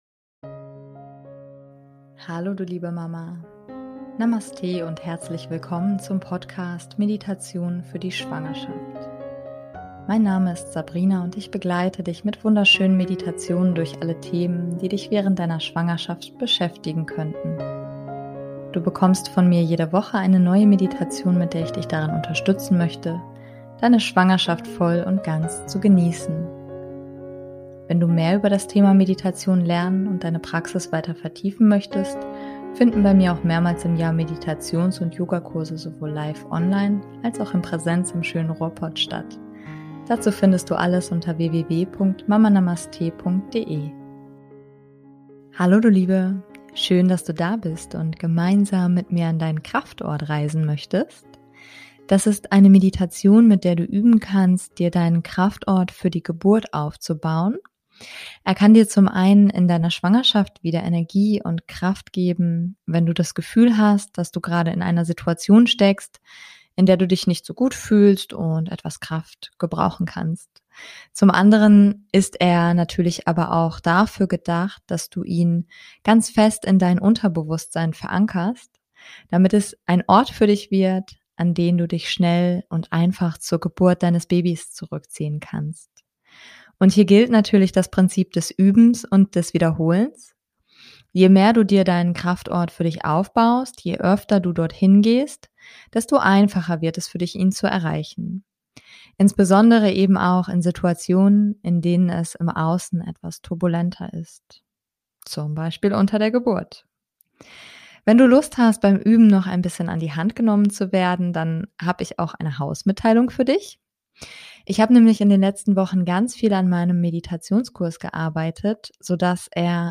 Dies ist eine Meditation, mit der du üben kannst, dir deinen Kraftort für die Geburt aufzubauen.